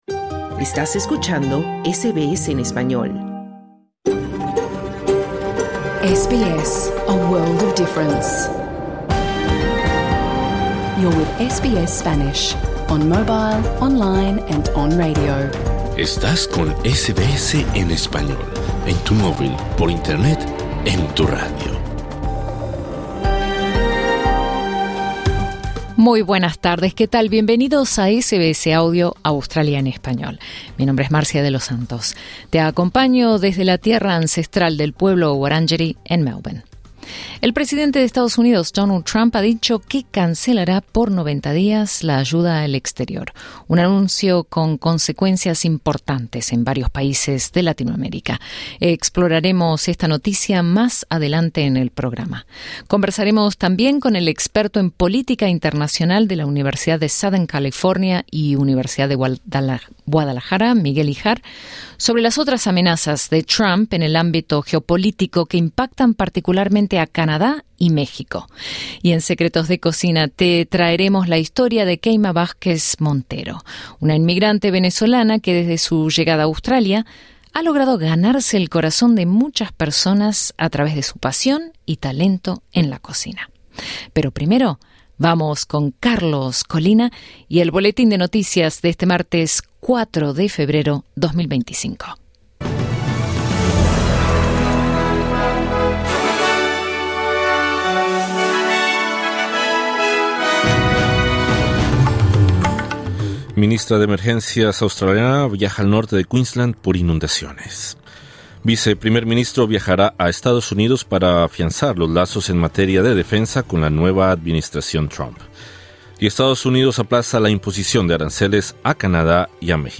Programa en vivo | SBS Spanish | 4 febrero 2025